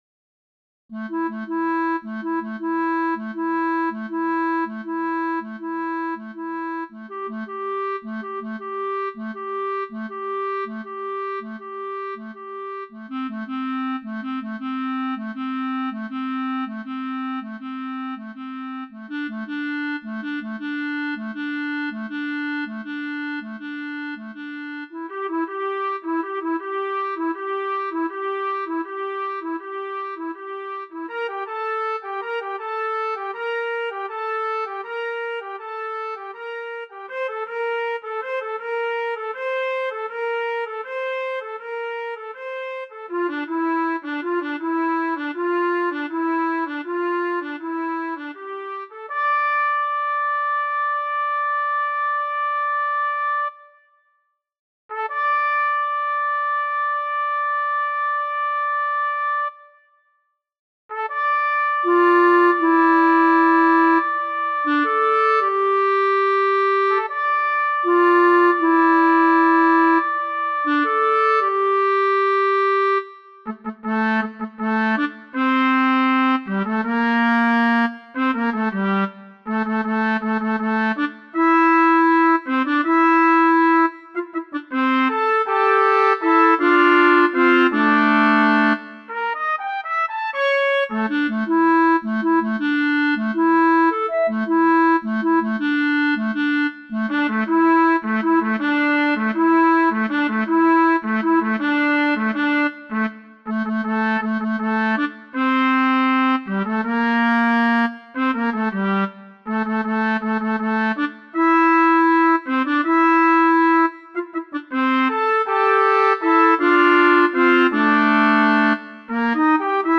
duet for clarinet and trumpet